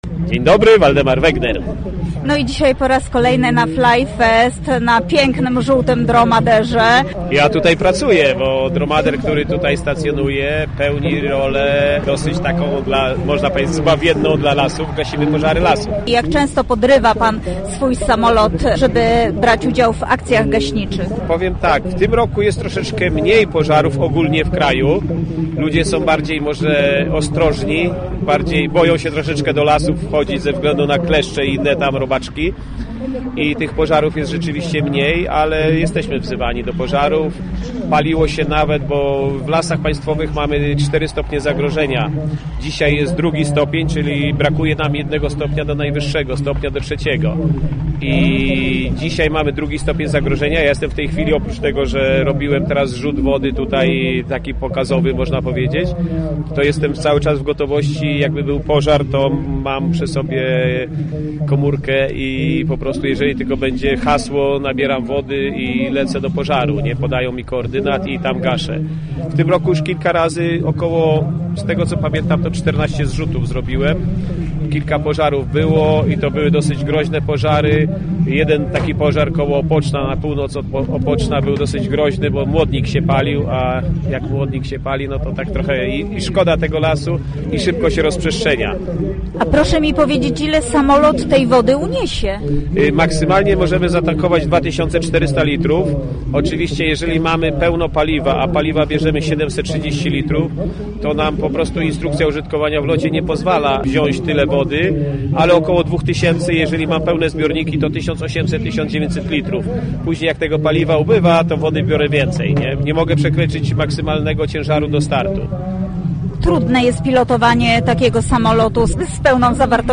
Pilotując samolot PZL M18 Dromader, gasi z powietrza pożary lasów. W rozmowie z nami mówi nam o swojej pracy i możliwościach samolotu.